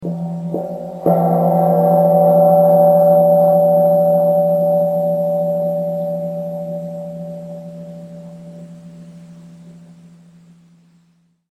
gong.mp3